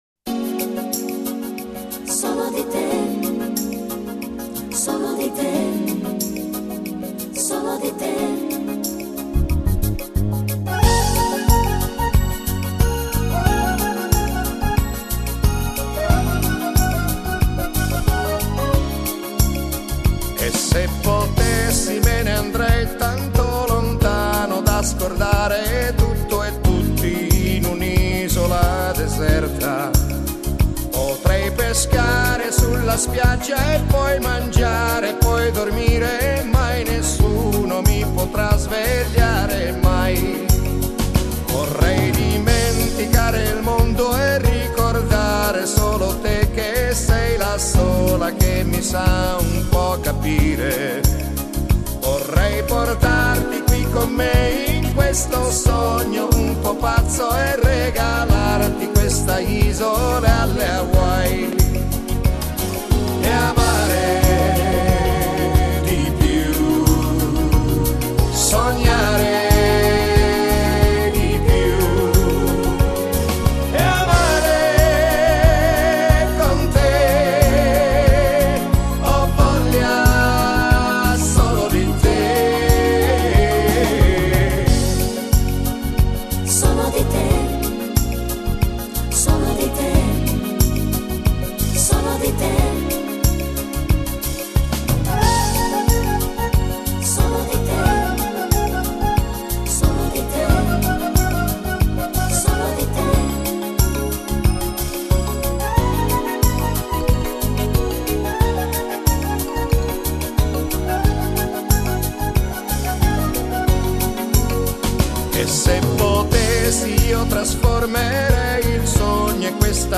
Genere: Reggae